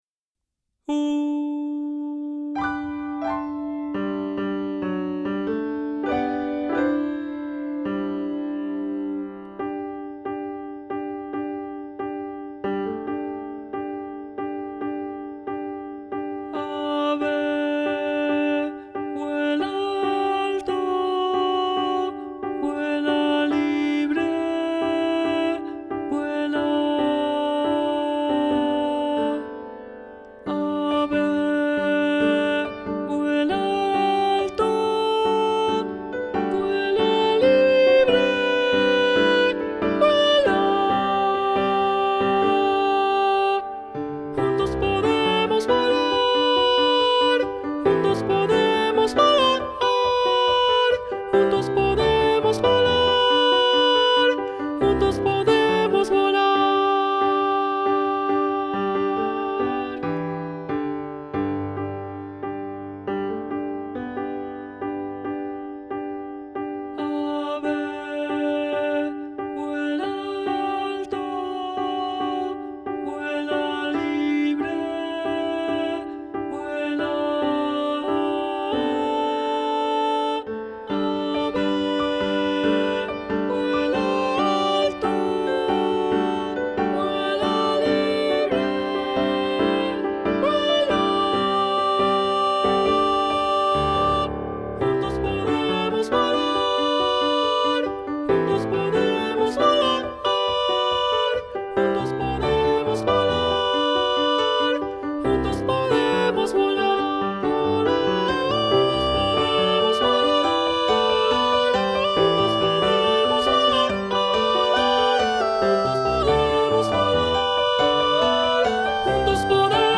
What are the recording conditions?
(Vocal Demo performance).